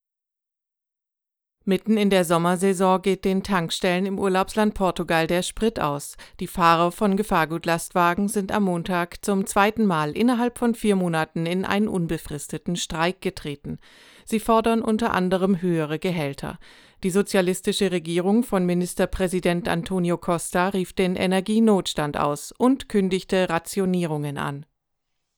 sprecherin
Demo Aufnahmen
Nachrichten
Nachrichten_04.wav